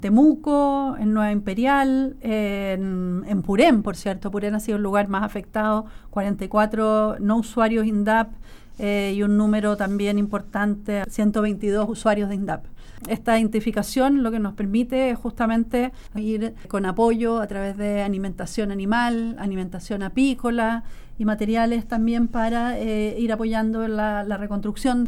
En conversación con La Radio, la ministra de Agricultura se refirió a la situación de los productores de cereales, tras revelar que las lluvias del verano están afectando el proceso de cosechas de avena, raps, trigo y cebada.